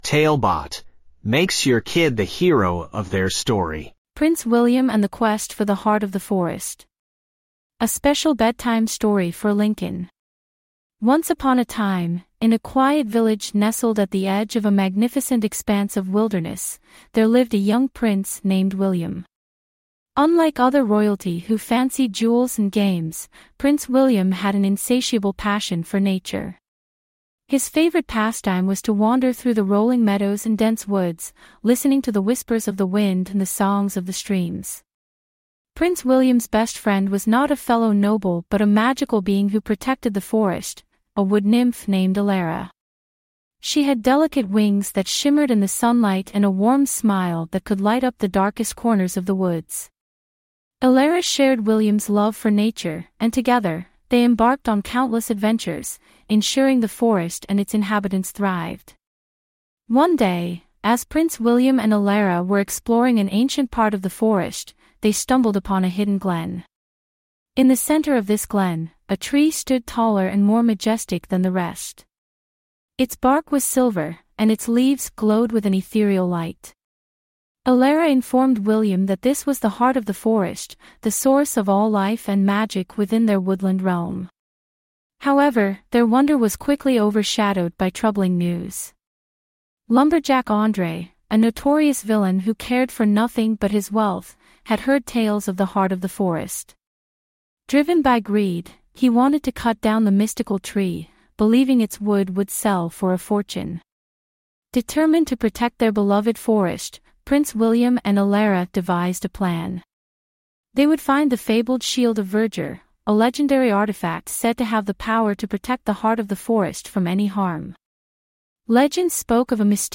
5 Minute Bedtime Stories
TaleBot AI Storyteller